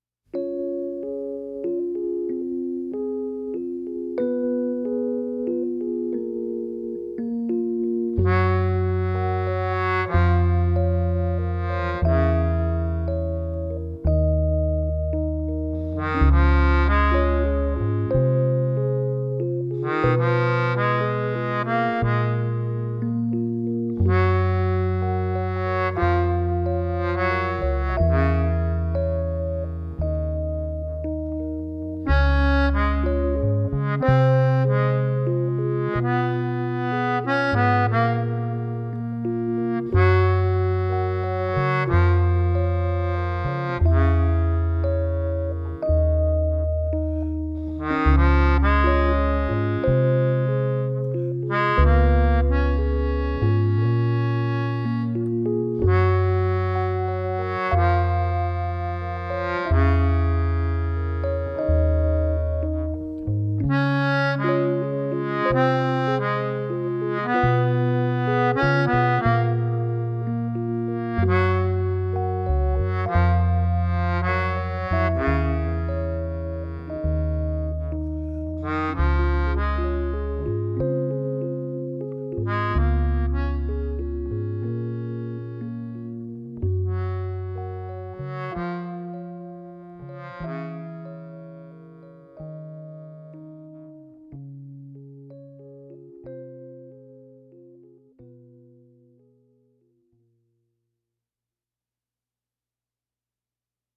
Genre: Indie, Alternative Rock, Original Soundtrack